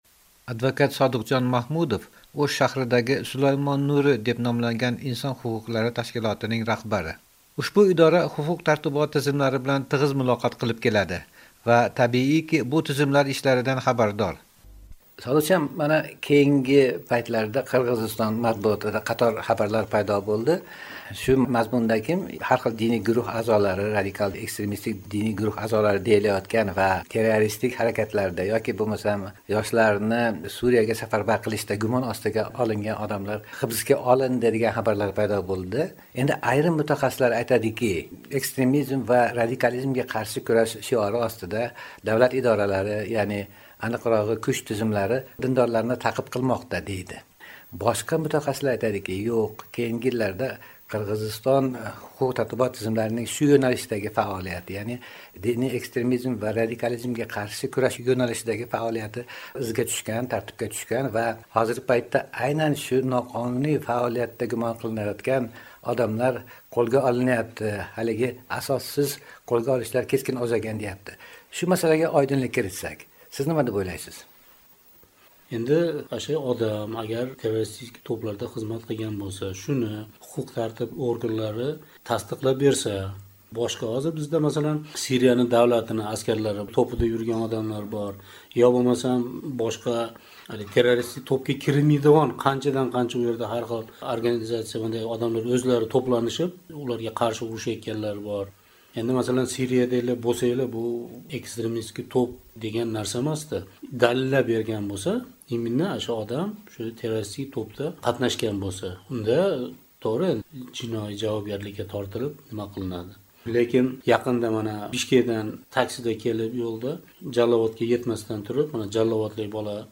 Ekstremizmga qarshi kurash haqida ekspertlar bilan suhbat, Qirg'iziston